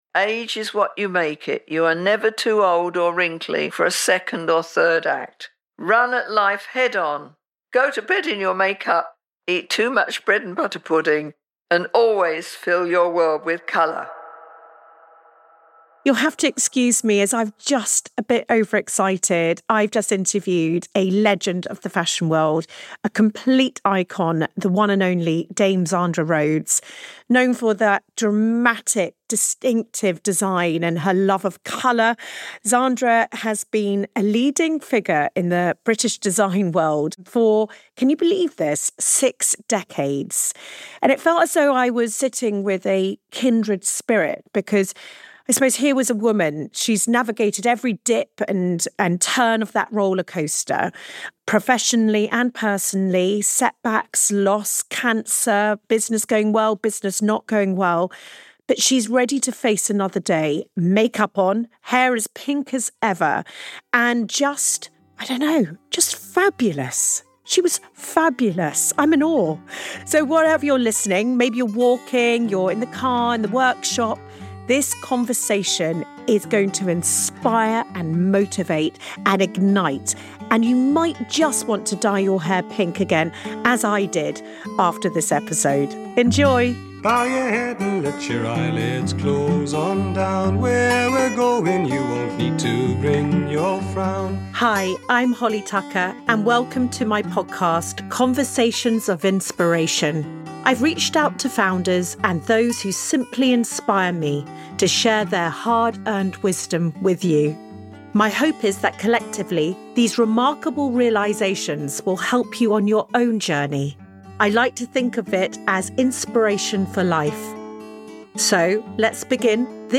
This conversation is packed with Zandra’s wisdom she has learned throughout her extraordinary life.